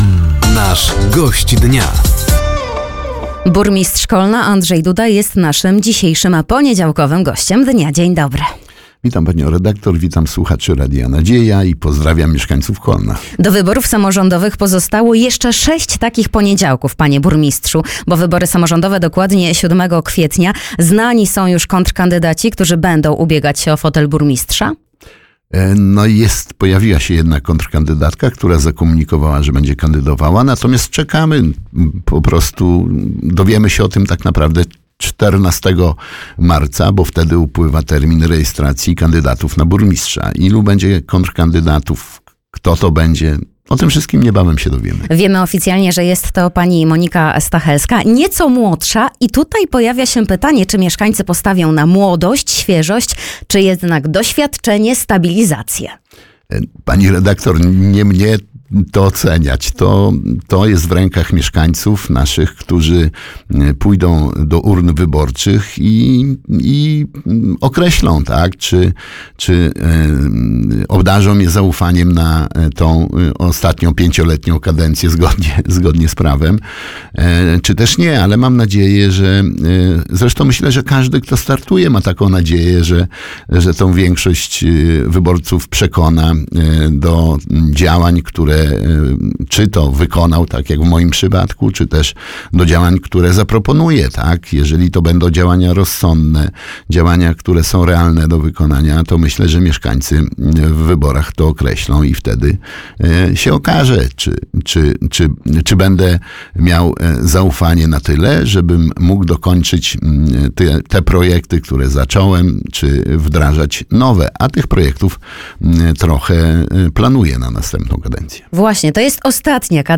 Gościem Dnia Radia Nadzieja był Andrzej Duda – Burmistrz Kolna. Temat rozmowy dotyczył rozpoczętej kampanii wyborczej, rewitalizacji miasta, a także nowych programów mających na celu poprawę komfortu życia młodych rodzin w mieście.